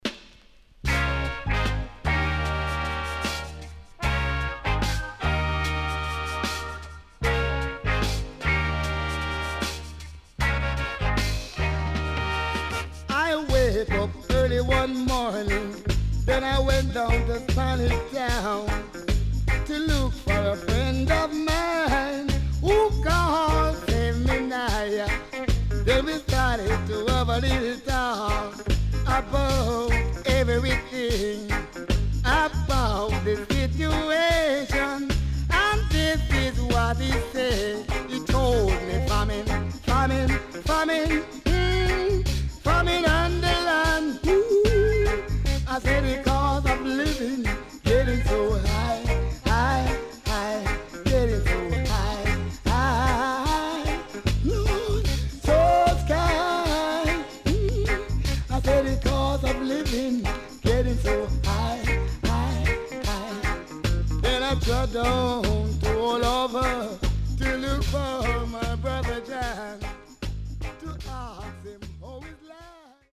HOME > REGGAE / ROOTS
CONDITION SIDE A:VG+
Self Remake.Reggae Take
SIDE A:少しチリノイズ入りますが良好です。